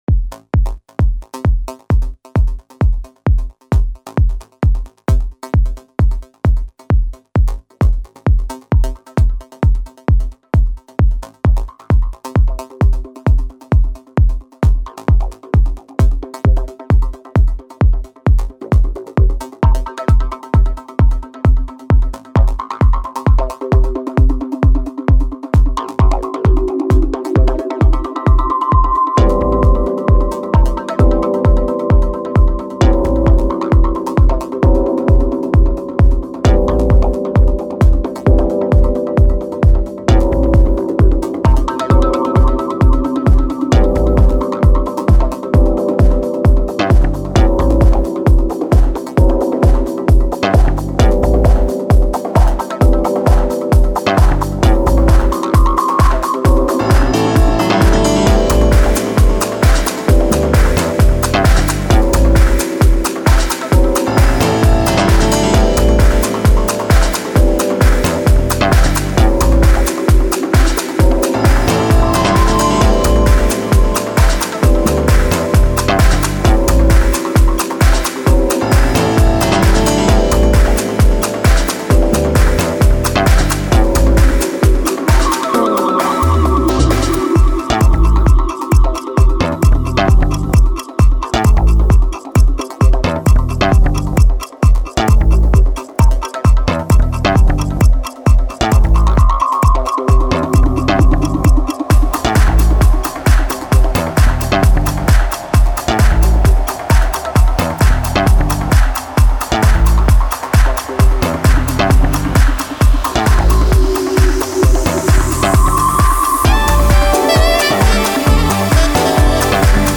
Minimal, Electronic, Jazz, Tech, Vintage, Oldschool - House